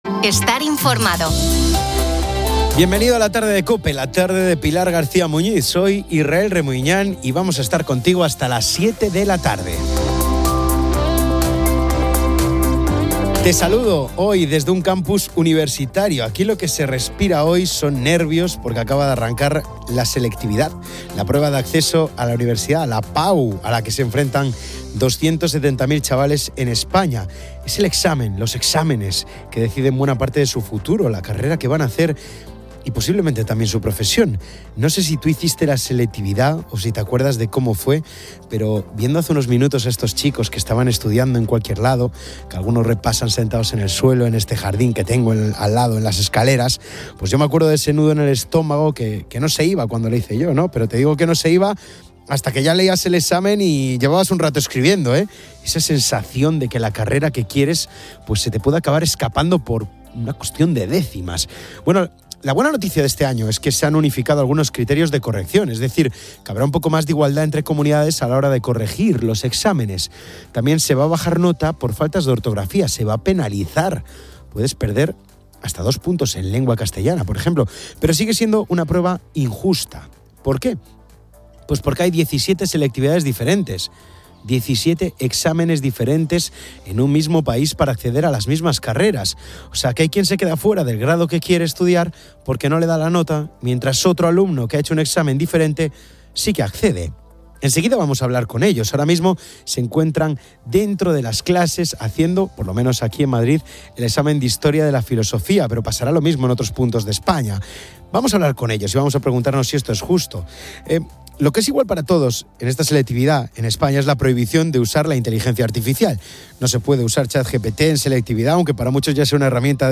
Bienvenido a la tarde de Cope, la tarde de Pilar García Muñiz.